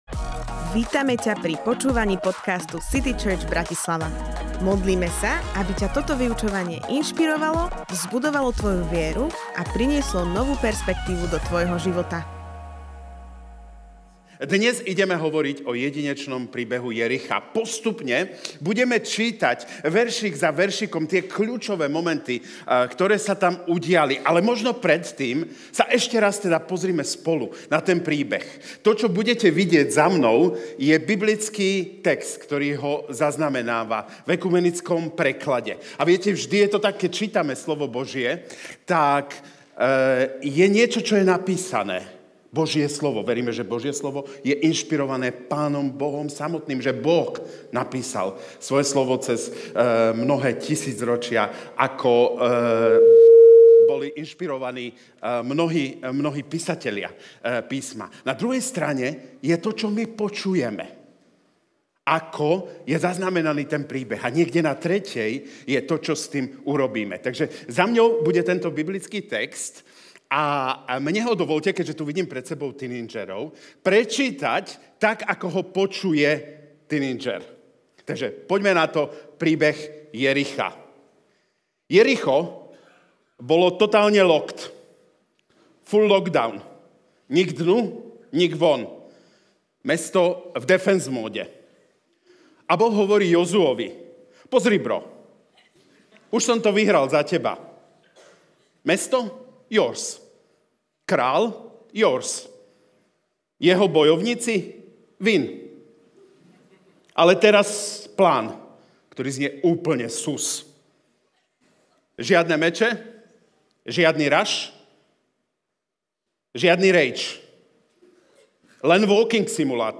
Ako sa rodí Boží zázrak Kázeň týždňa Zo série kázní